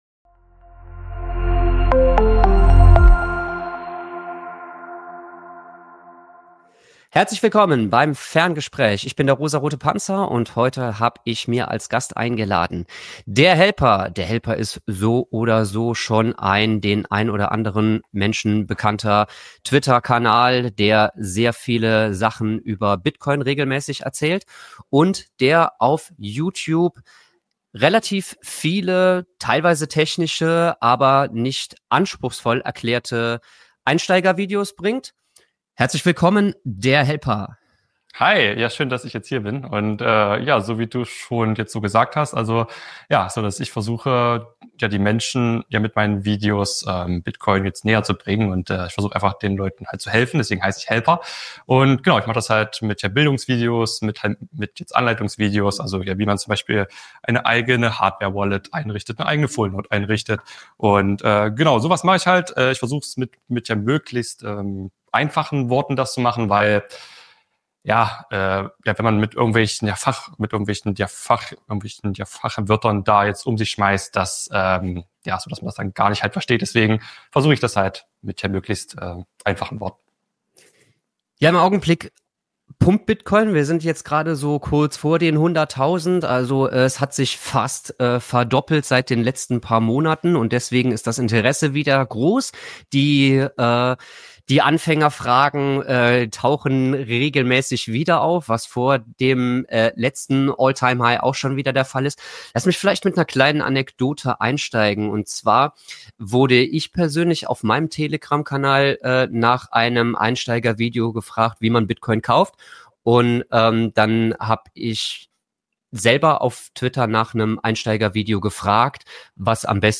Außerdem geht es um grundlegende Aspekte wie die Funktion von Fullnodes, die Vorteile von Mining und die langfristige Bedeutung von Bitcoin als alternatives Geldsystem. Ein Gespräch voller praktischer Tipps und wertvoller Einblicke für jeden, der Bitcoin wirklich verstehen und nutzen möchte.